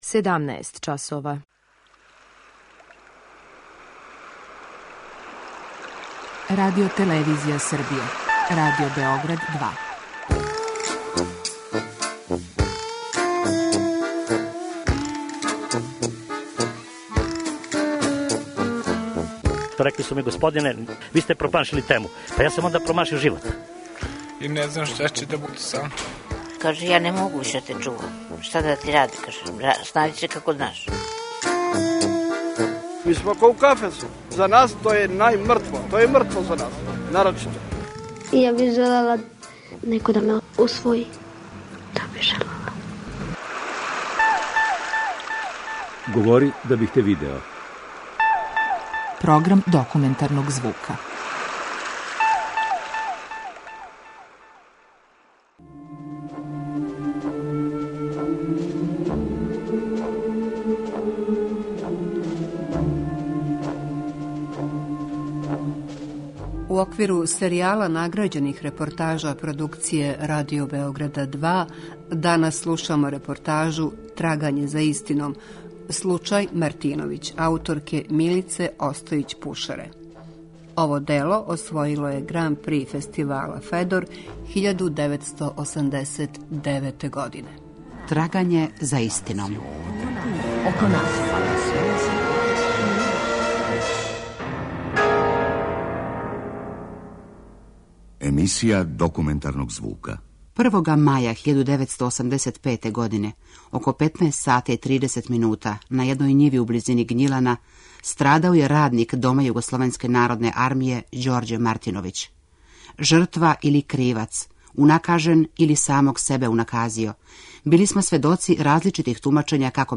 Документарни програм: Серијал награђених репортажа
Ова оригинална продукција Радио Београда 2 сједињује квалитете актуелног друштвеног ангажмана и култивисане радиофонске обраде.